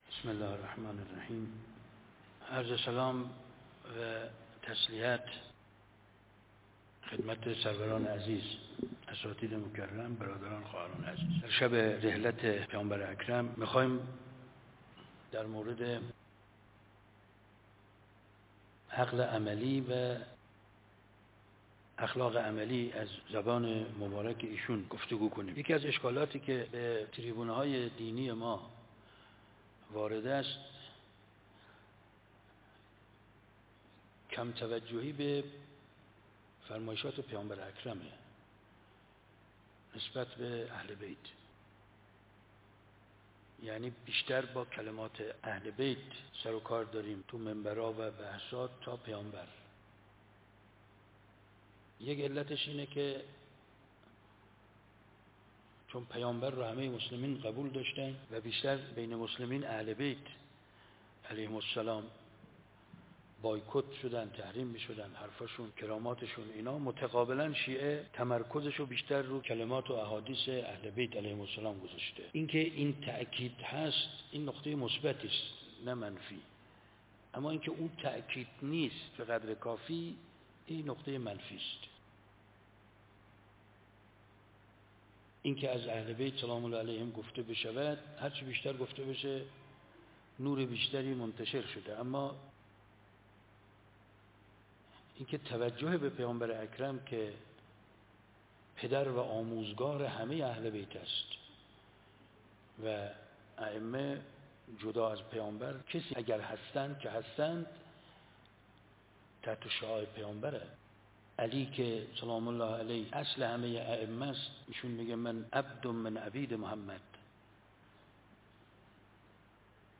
نشست (وداع با پیام‌آور یا پیام؟) _ دانشگاه فردوسی مشهد _ رحلت پیامبر اکرم ص _ ۱۴۰۲